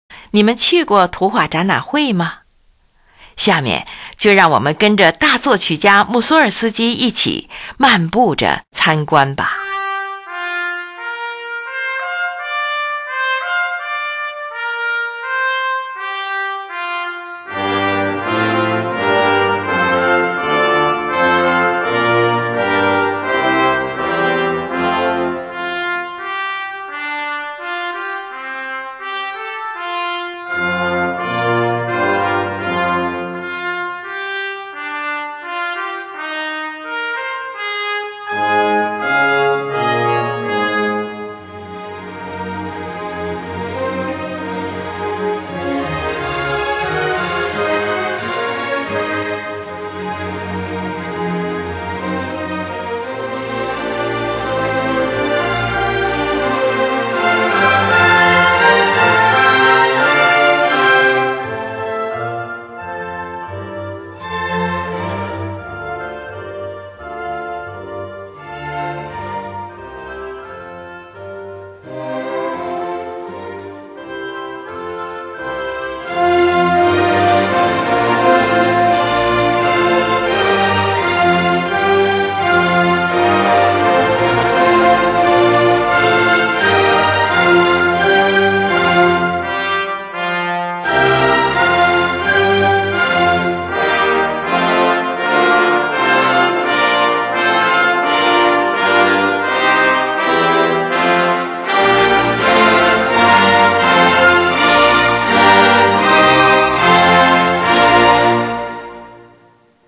后来有几位作曲家将这首钢琴组曲改编成管弦乐曲